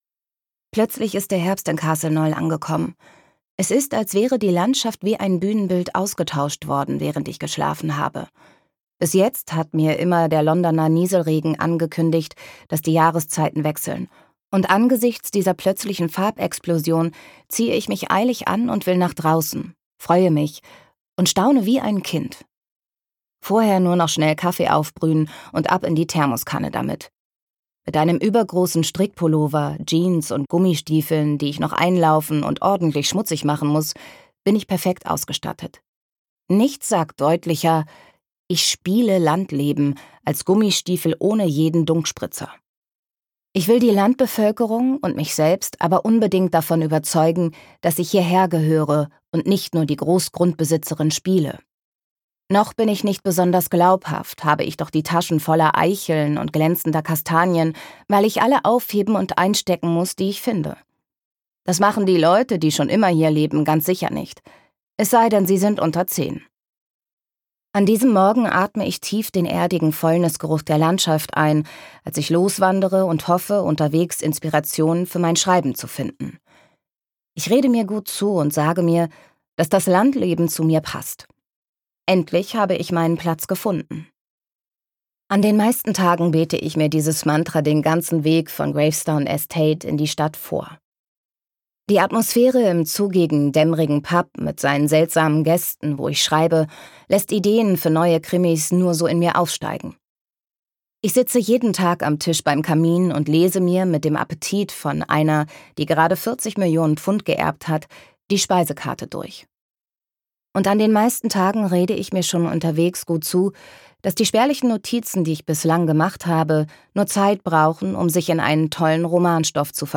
Das ist, zumindest beim Hörbuch, teilweise etwas schwierig.